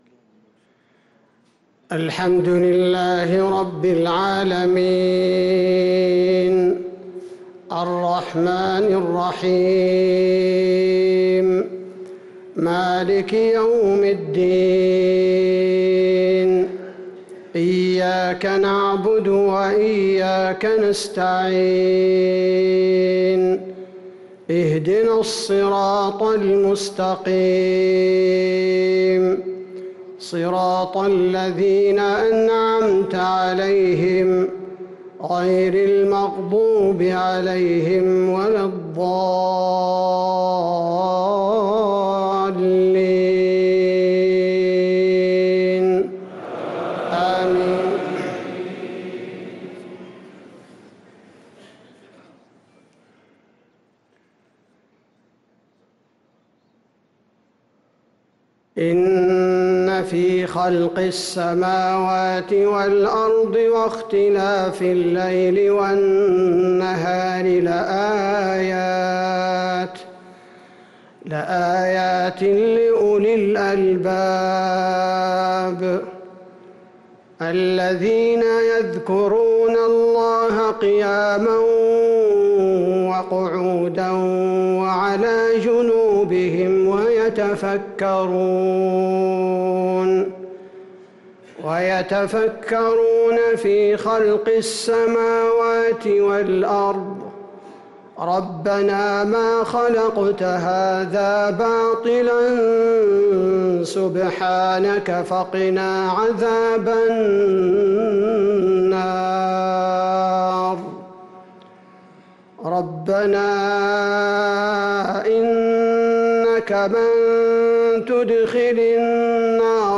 صلاة المغرب للقارئ عبدالباري الثبيتي 9 ذو الحجة 1444 هـ